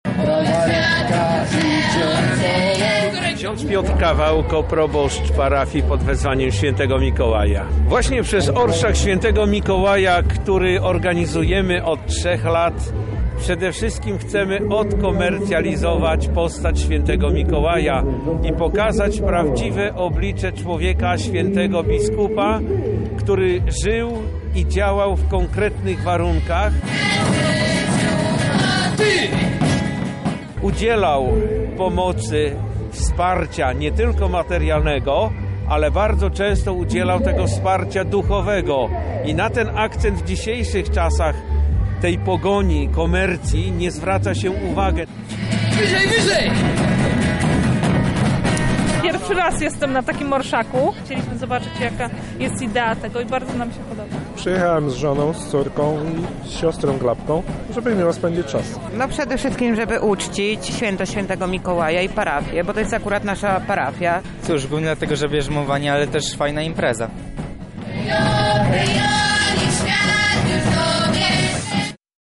Wydarzeniu przyglądała się nasza reporterka: